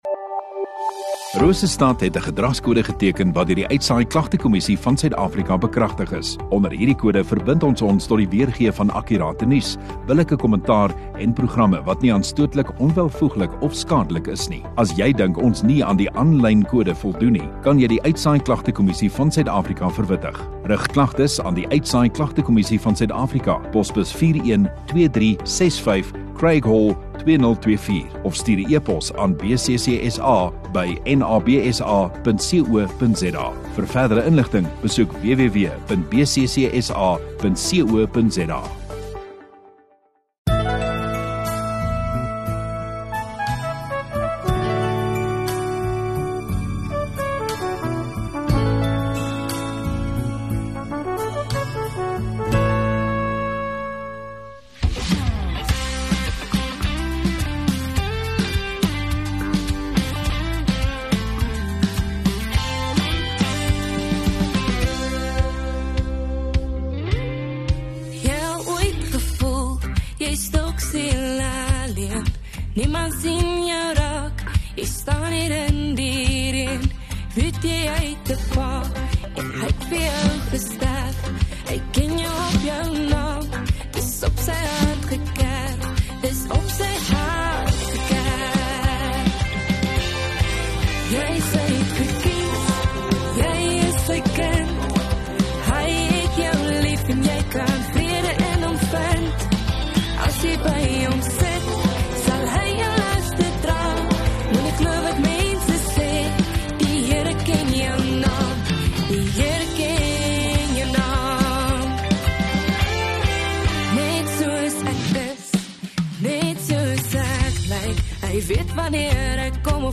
20 Dec Vrydag Oggenddiens